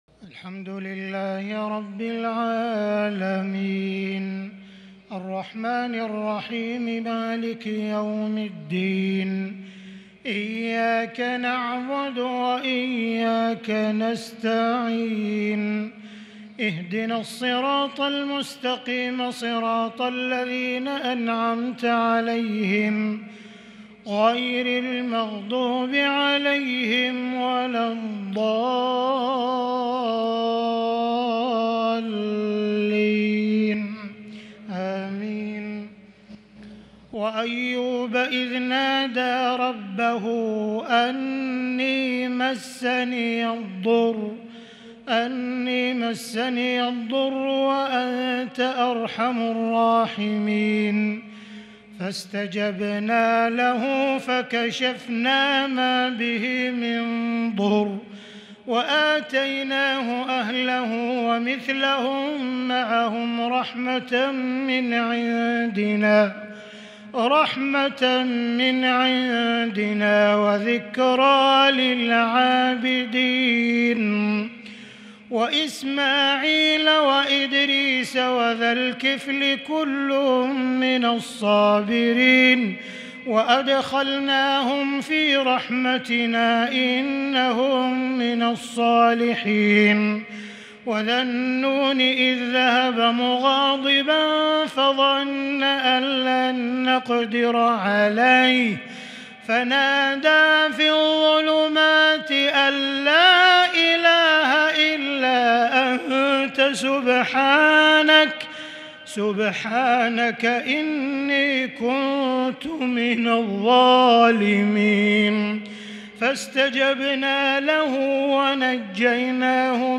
صلاة التهجّد| ليلة 21 رمضان 1442| من سورتي الأنبياء 83-112 و الحج1-16 Tahajjud prayer | The night of Ramadan 21 1442 | Surah Al-Anbiyaa and Al-Hajj > تراويح الحرم المكي عام 1442 🕋 > التراويح - تلاوات الحرمين